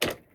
gear_rattle_weap_launcher_06.ogg